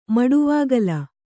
♪ maḍuvāgala